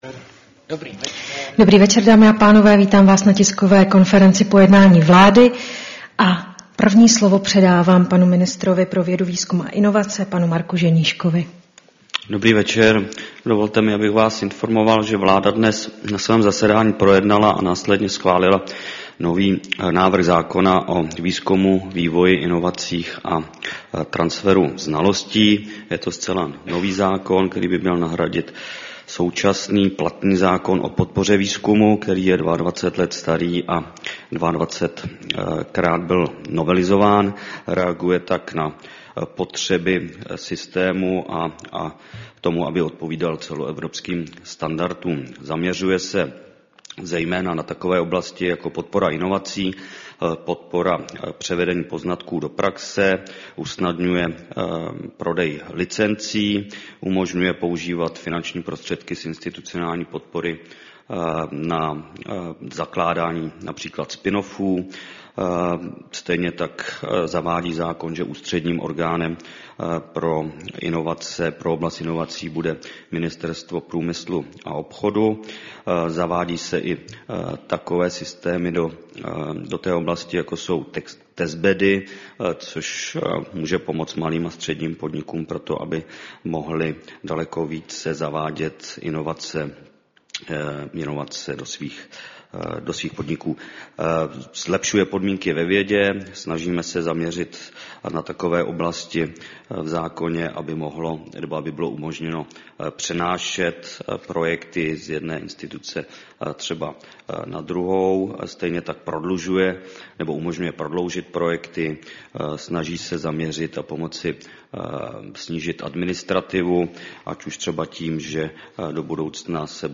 Tisková konference po jednání vlády, 18. prosince 2024